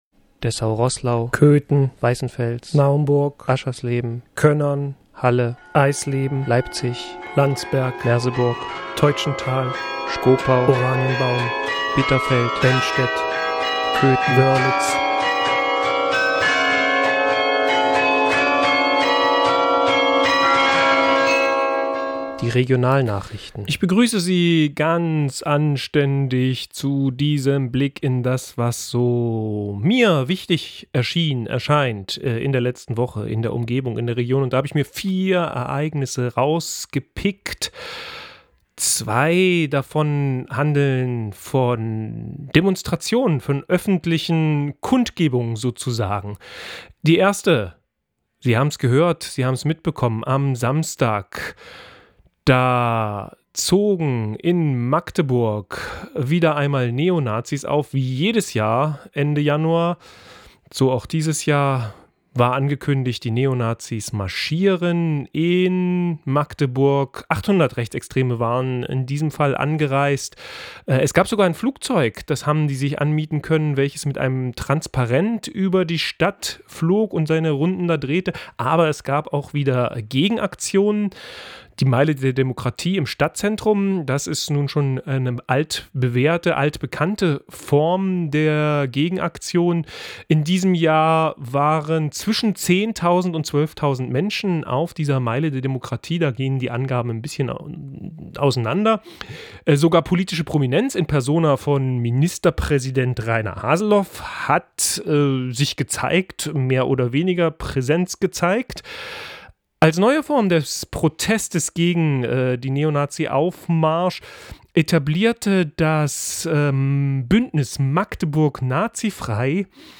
Regionalnachrichten vom 21. Januar 2014